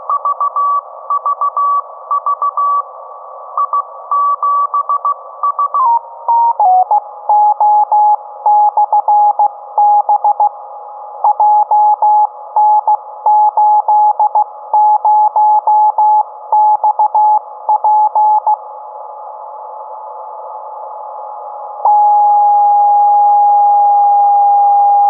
REGISTRAZIONI DEGLI ECHI LUNARI DEI BEACONS E DEI QSO